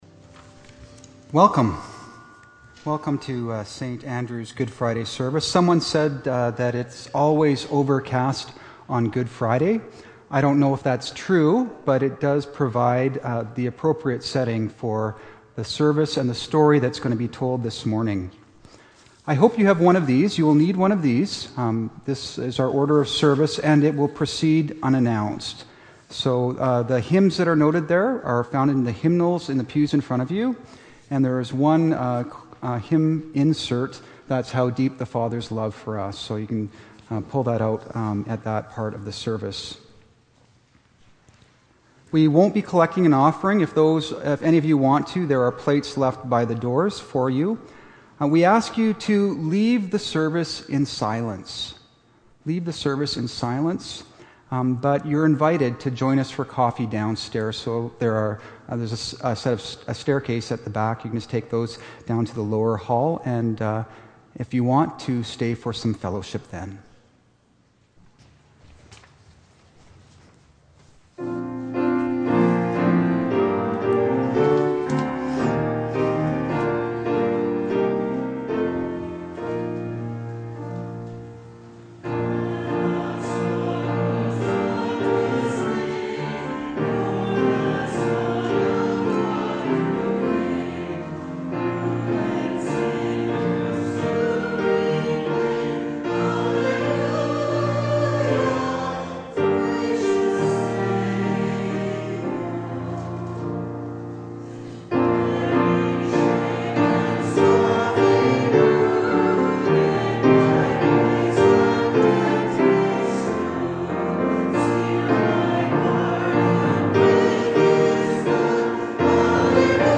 Join us this Good Friday and witness the story of Jesus’ suffering and death, expressed in music and drama.
Sermon Notes